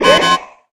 enemy detected.wav